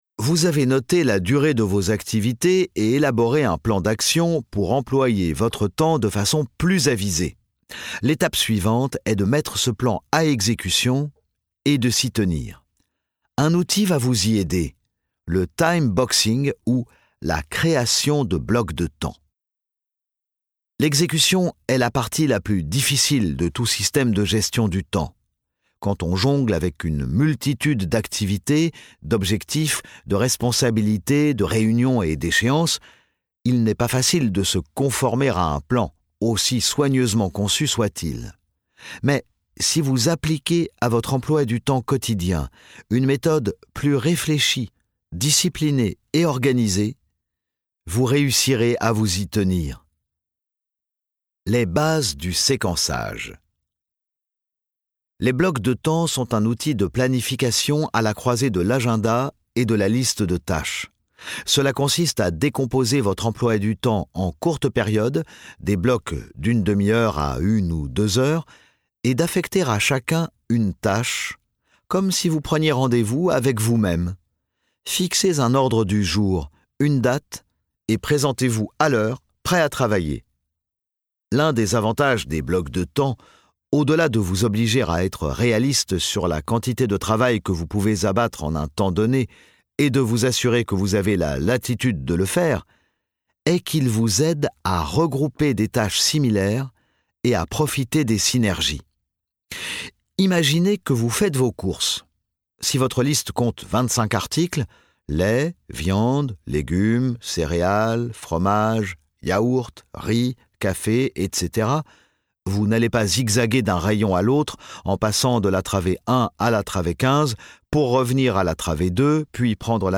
Extrait gratuit - Gagnez en efficacité de Harvard Business Review